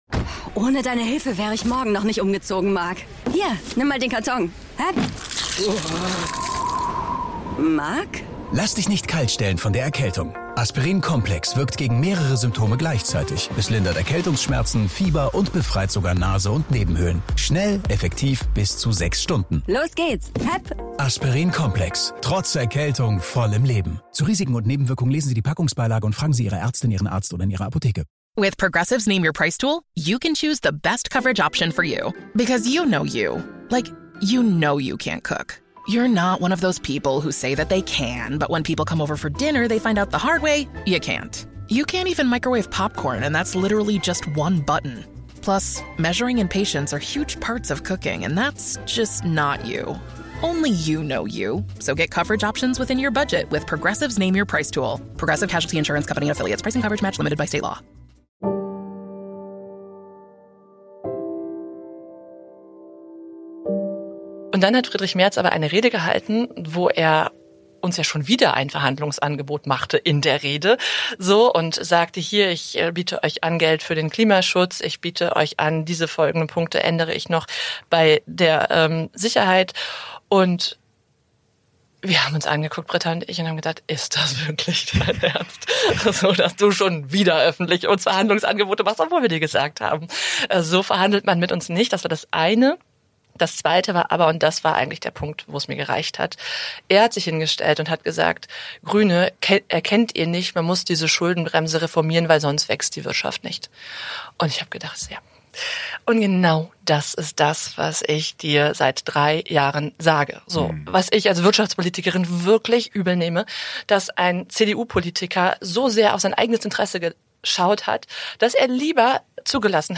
Spitzenpolitikerinnen und -politiker im Gespräch